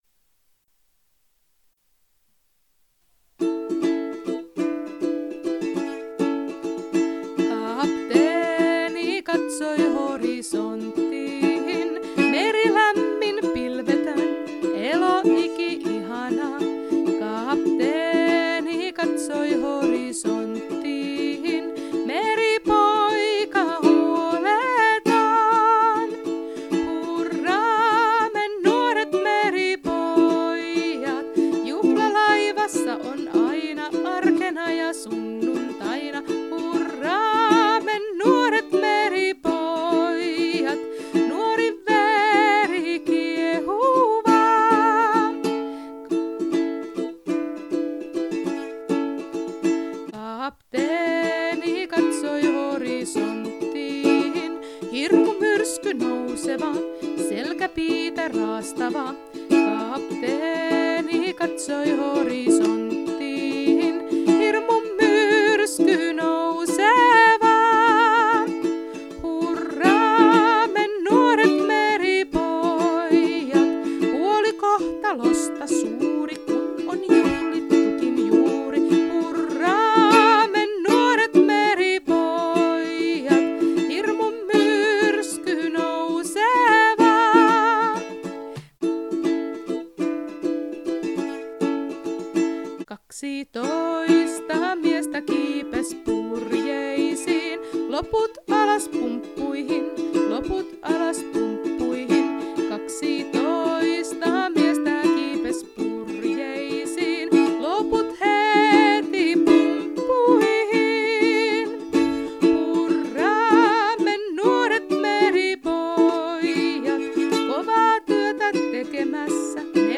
USKONTOKRIITTINEN LAULU Laulu